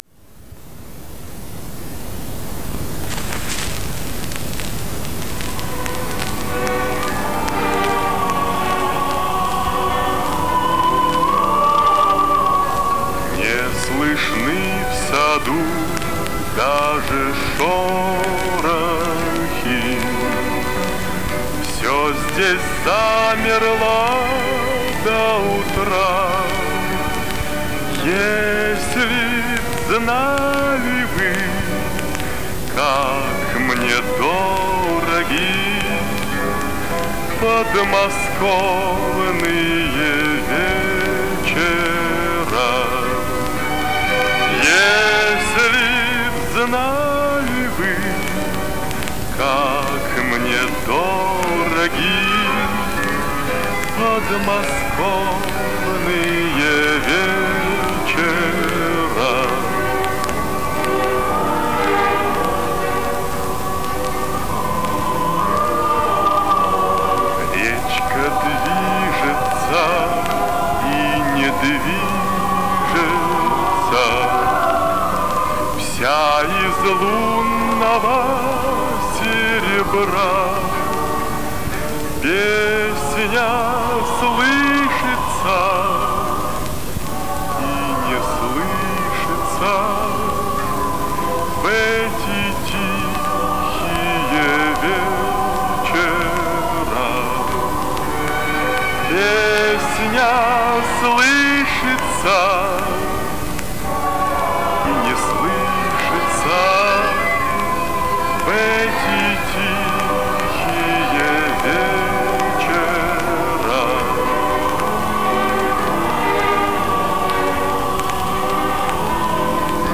Die Musikstücke habe ich jetzt digitalisiert und aufgepeppt.